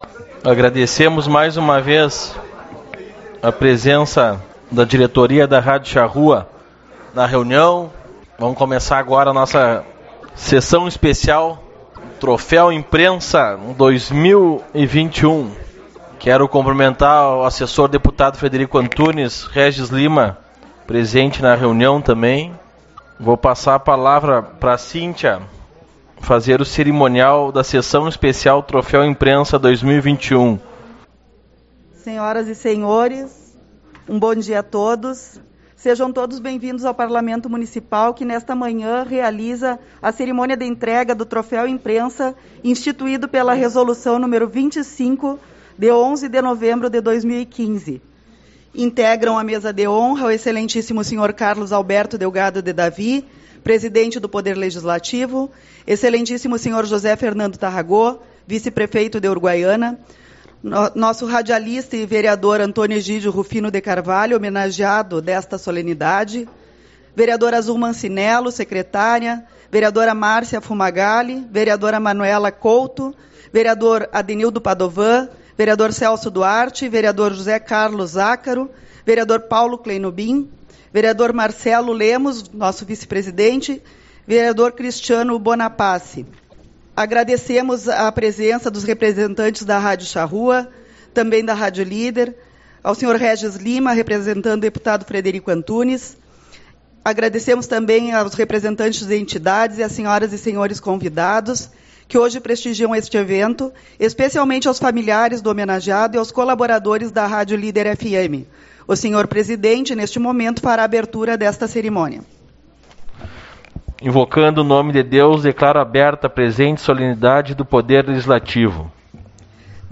21/10 - Sessão Especial-Troféu Imprensa